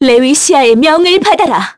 Lewsia_B-Vox_Skill7-2_kr.wav